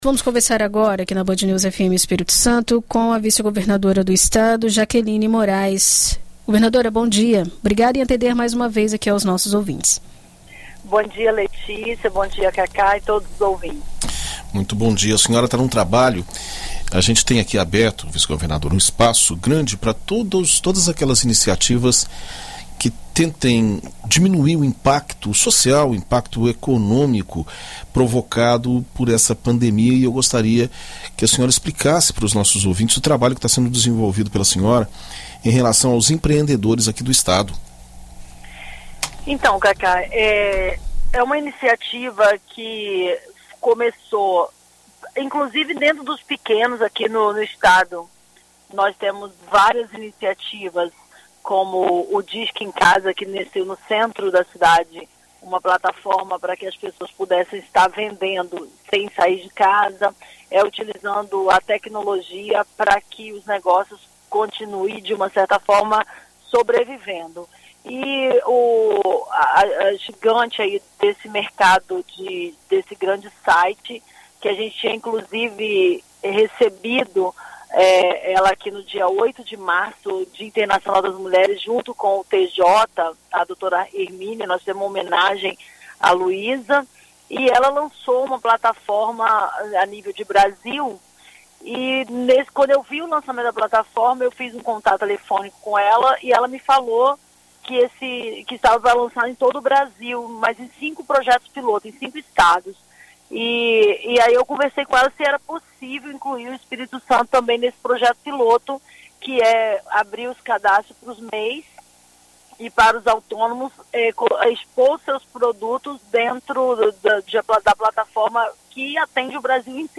Em entrevista à BandNews FM, nesta segunda-feira (13), a vice-governadora detalhou a proposta e mais medidas que auxiliem os microempreendedores do Espírito Santo.
ENT-VICE-GOVERNADORA.mp3